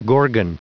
Prononciation du mot gorgon en anglais (fichier audio)
Prononciation du mot : gorgon